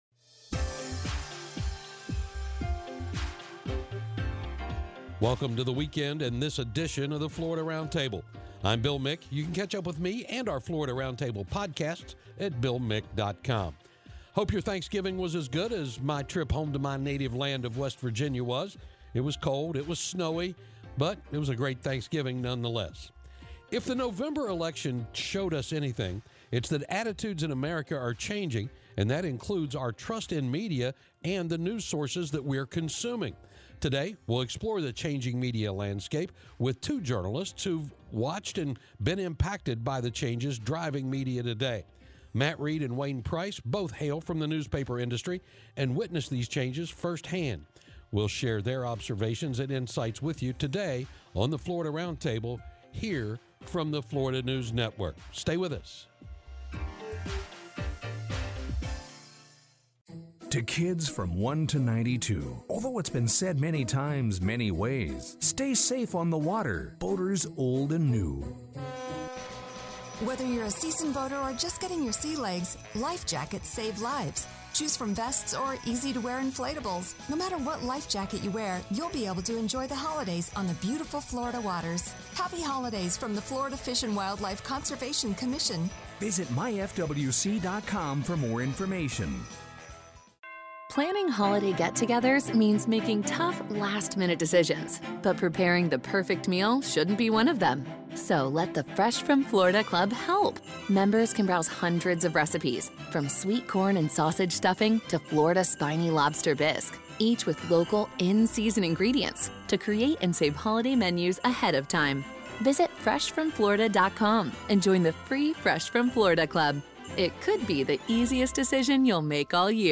FNN's Florida Roundtable is a weekly, one-hour news and public affairs program that focuses on news and issues of Florida.